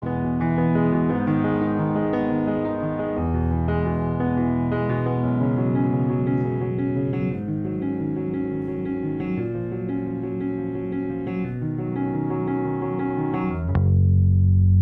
Comentarios Sobre el audio: [0:00-0:05] Piano. [0:05-0:13] Piano con Ecualización.